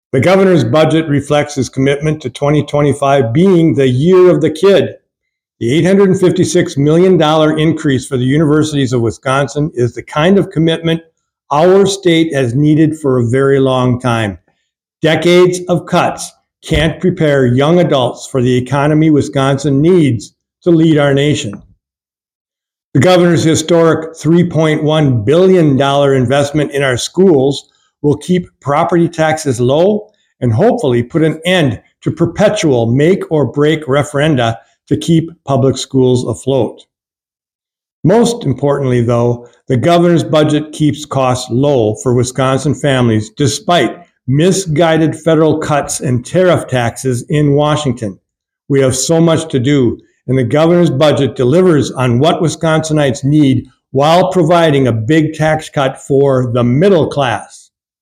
Sen. Smith’s Statement on Gov. Evers’ 2025-27 Biennial State Budget Address